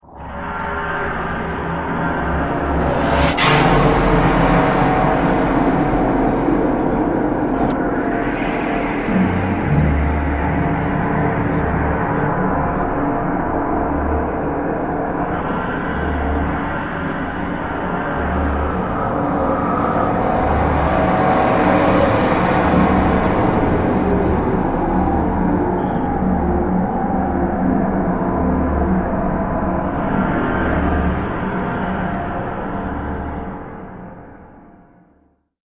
• Piste 09  -  [1'35]  -  Industrielle ;)